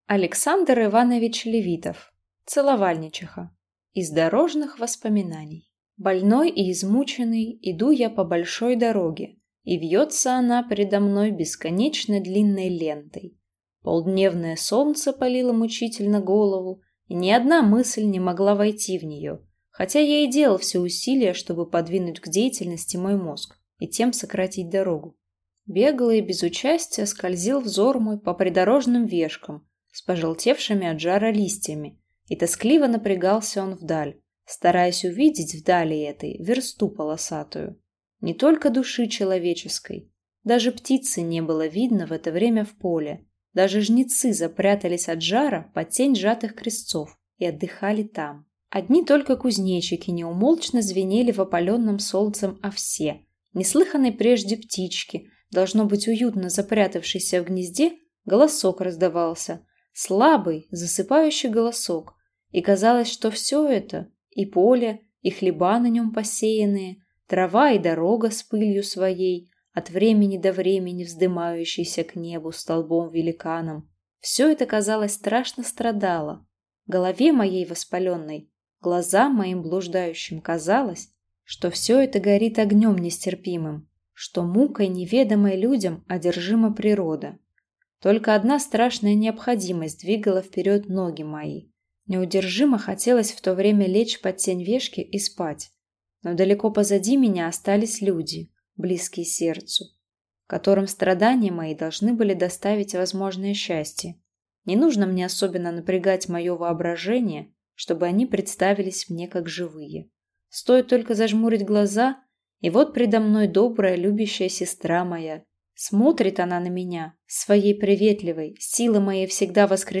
Аудиокнига Целовальничиха | Библиотека аудиокниг